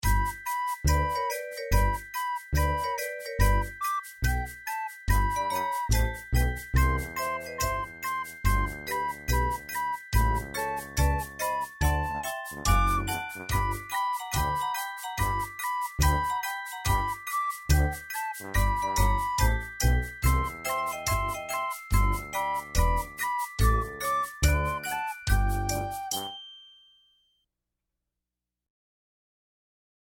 Voicing: Soprano Re